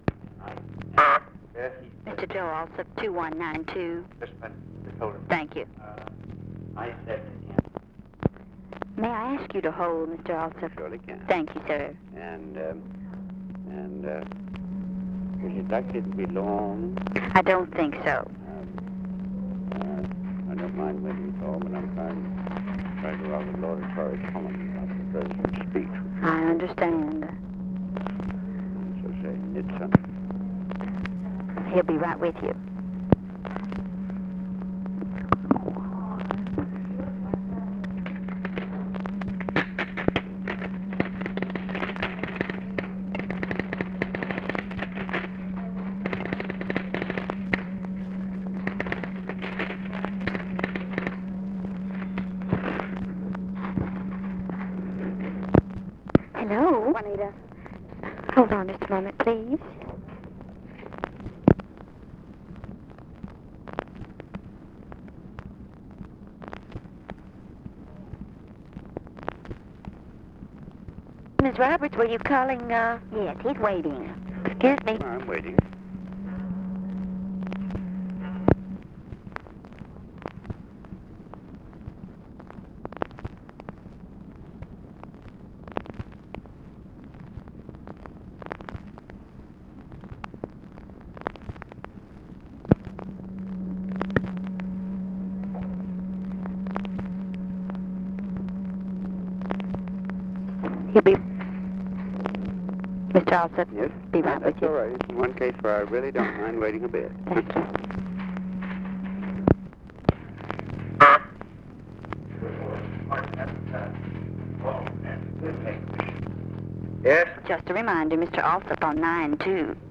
Conversation with JOSEPH ALSOP, November 27, 1963
Secret White House Tapes